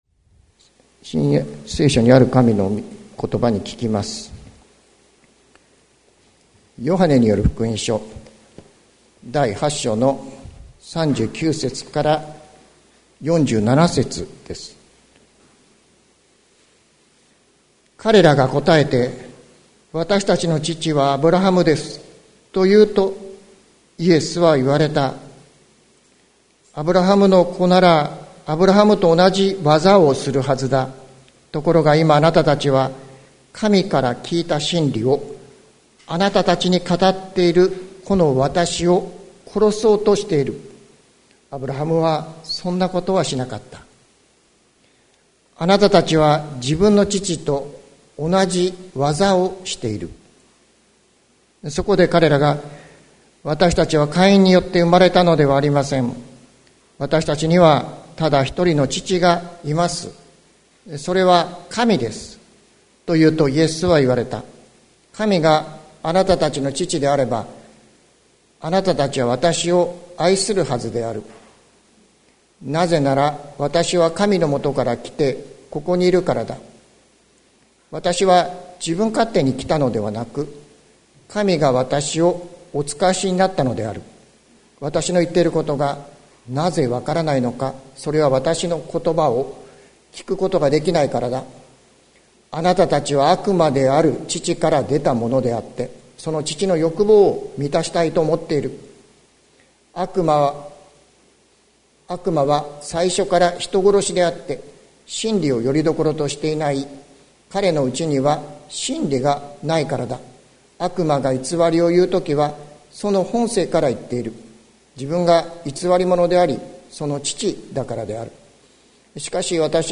2022年08月21日朝の礼拝「神の愛によって神の子として生きる」関キリスト教会
説教アーカイブ。